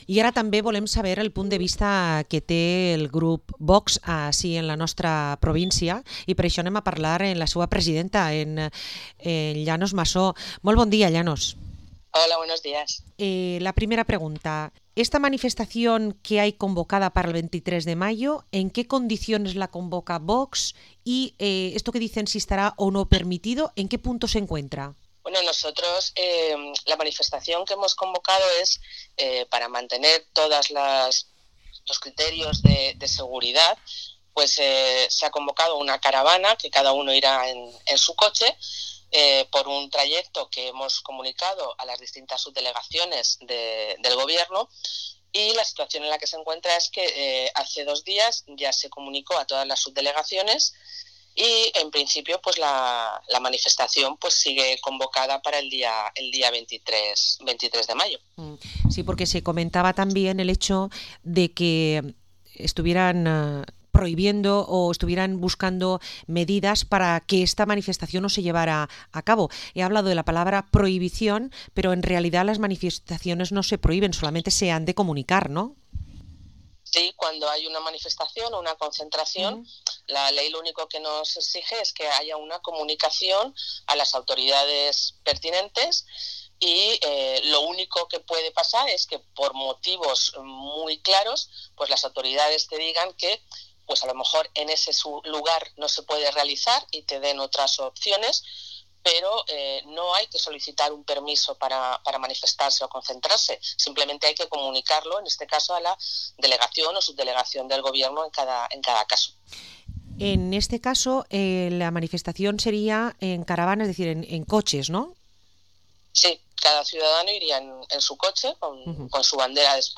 Entrevista a la diputada autonómica de VOX, Llanos Masó